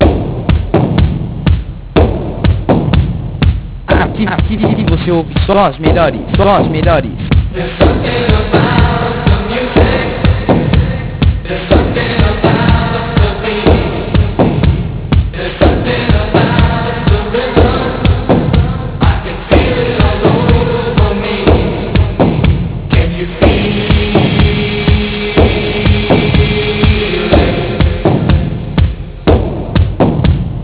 Aqui Algumas De Nossas Vinhetas e Chamadas
Todas Produzidas Pelos Dj's Da Rádio